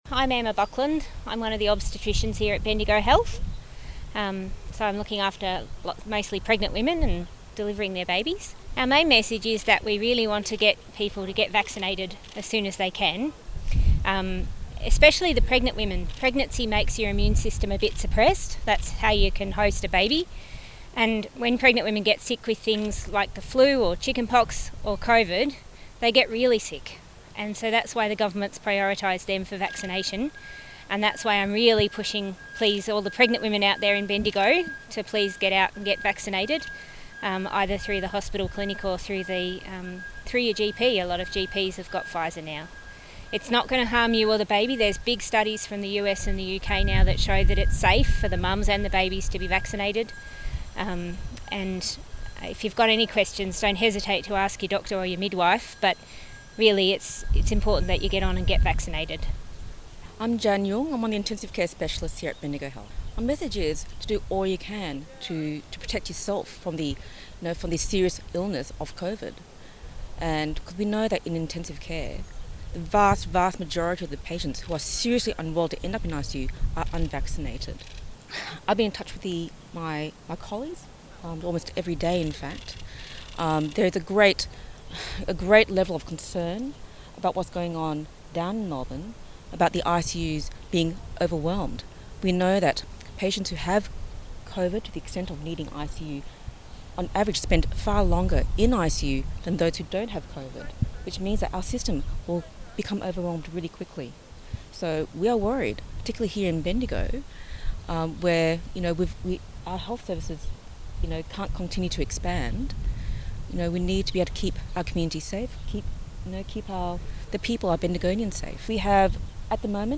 A group of Bendigo doctors are urging the regions unvaccinated population to roll up their sleeves as the COVID Delta strain spreads across the state. Doctors with specialities in respiratory and emergency medicine, intensive care, obstetrics and gynaecology and general practice spoke about the importance of high vaccination rates across our region as we enter the next phase of the pandemic where COVID cases will be more widespread in our community.